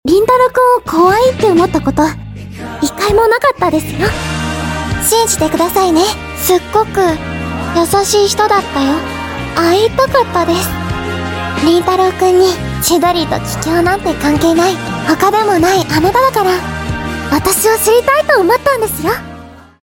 Even if my voice isn't similar to Waguri, I hope my tone is soft enough to express my efforts towards her gentle and cheerful character!